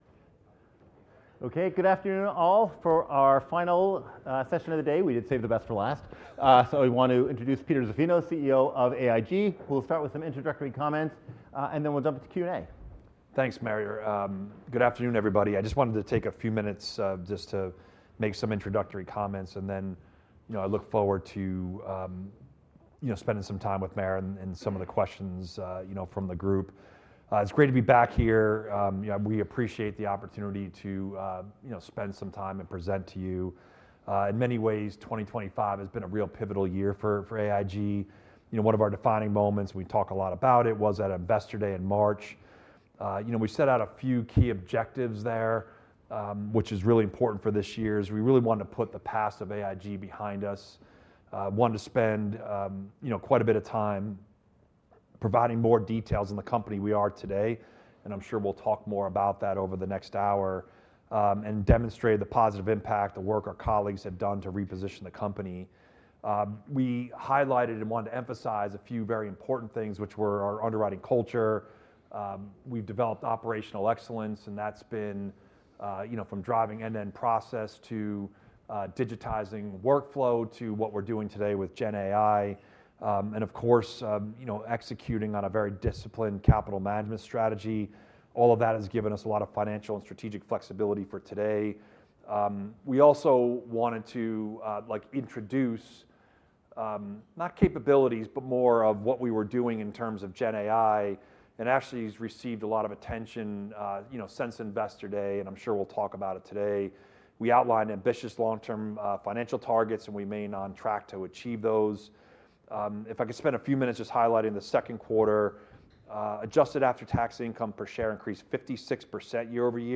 kbw-insurance-conference-call.mp3